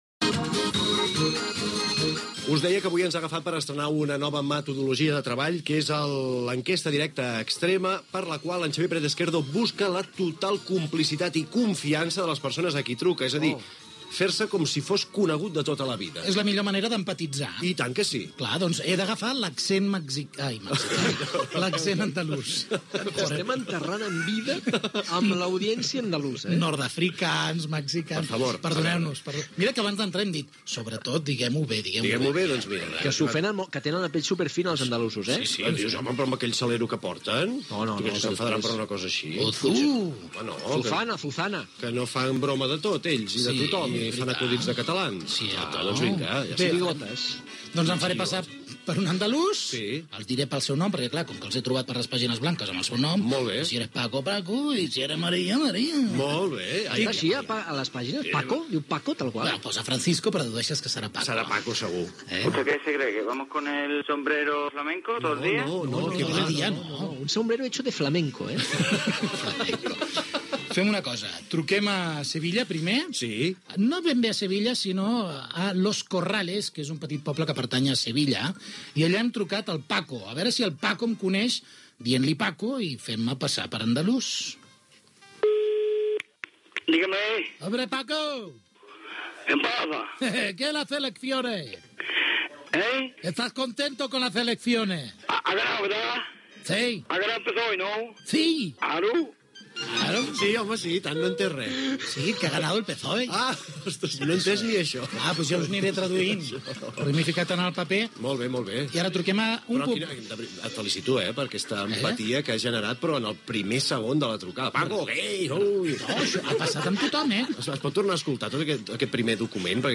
"Treball de camp" amb trucades telefòniques a Andalusia el dia després de les seves eleccions autonòmiques Gènere radiofònic Entreteniment